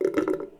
sink-churrrp
bath bubble burp churp click drain dribble drip sound effect free sound royalty free Sound Effects